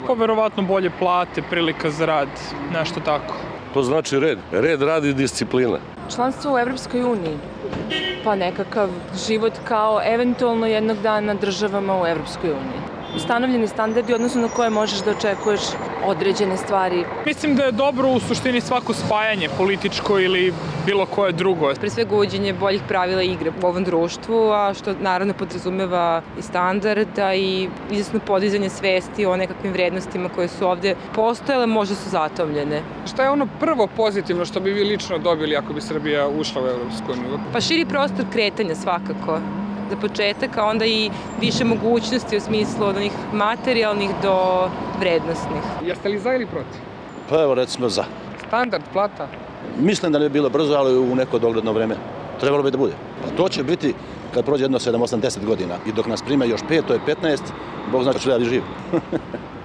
Anketa Evropska unija, Beograd